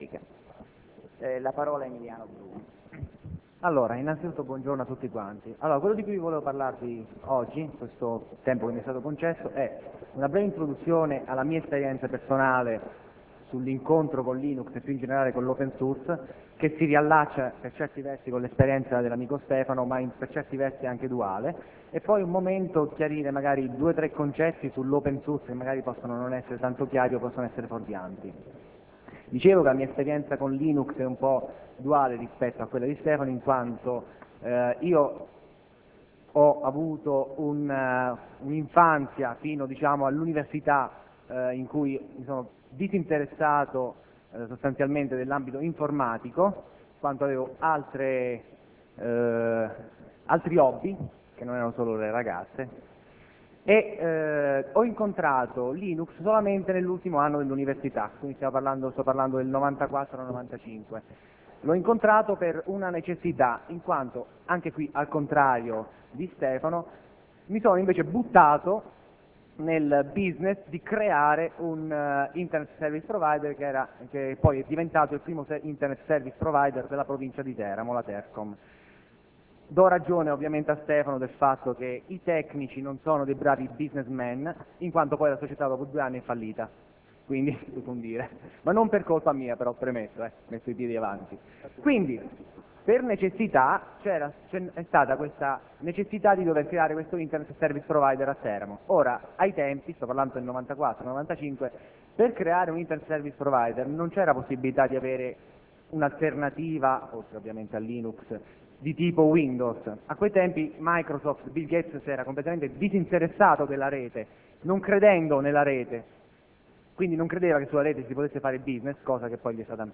Nel 2002 la mia partecipazione al LinuxDay si è svolta presso l'aula magna della facoltà di farmacia presso l'università di Chieti nell'ambito dell'evento organizzato dal Linux users group di Chieti. In questo intervento, ho riassunto la mia esperienza giovanile nel mondo dell'informatica e il mio incontro con il sistema operativo Linux.